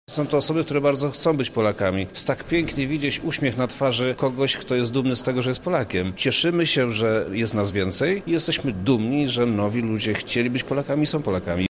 W Lublinie w imieniu Prezydenta Polski obywatelstwa wręczał wojewoda Przemysław Czarnek. Dla niego również był to ważny moment a o nowych obywatelach mówi.